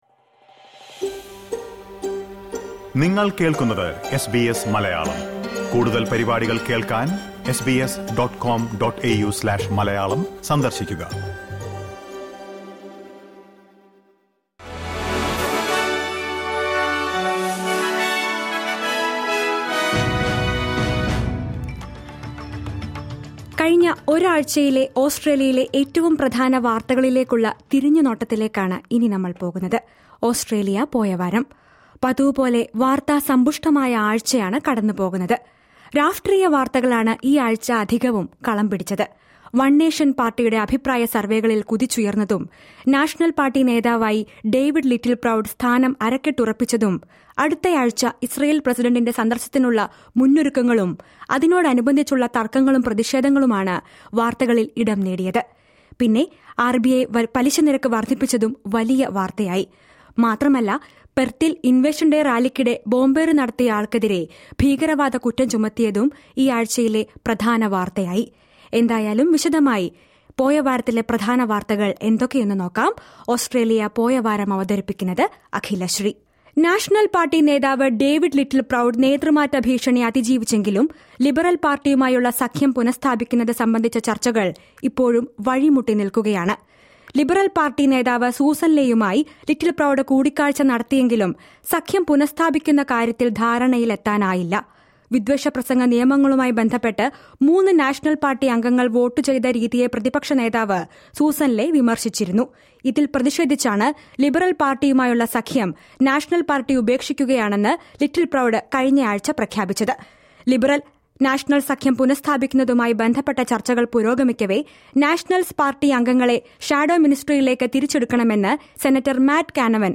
ഓസ്ട്രേലിയയിലെ ഇക്കഴിഞ്ഞയാഴ്ചയിലെ ഏറ്റവും പ്രധാന വാർത്തകൾ കേൾക്കാം, ചുരുക്കത്തിൽ.....